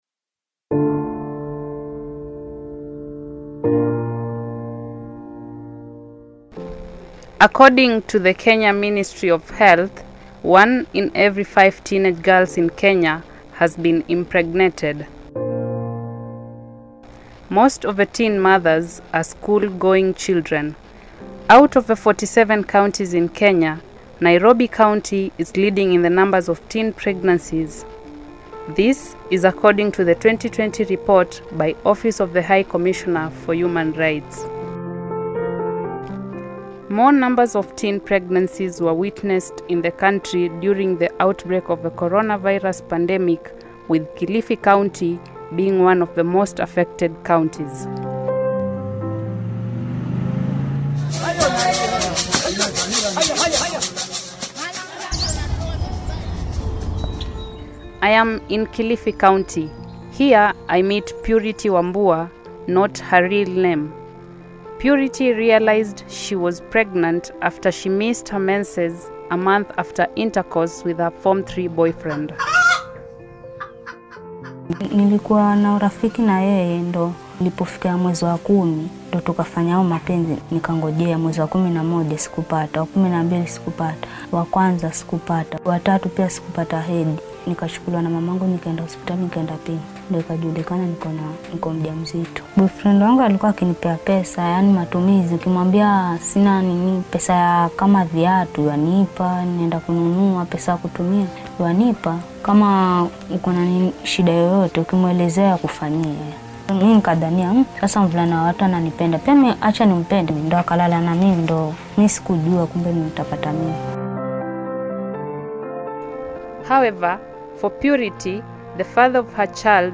RADIO-DOCUMENTARY-ON-TEEN-PREGNACIES.mp3